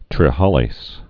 (trĭ-hälās, -lāz)